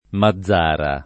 mazz#ra] (antiq. Mazzara [id.]) top. (Sic.) — M. del Vallo, nome uffic. della città; Val di M., nome stor. del «vallo» che comprendeva la Sic. occid. e aveva M. per capoluogo — sim. i cogn. Mazara, Mazzara — con diverso accento, il fiume Mazaro [m#zzaro] che sbocca in mare a Mazara